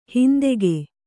♪ hindege